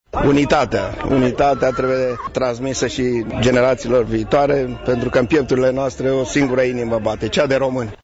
Subprefectul Brașovului, Adrian Folea: